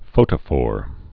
(fōtə-fôr)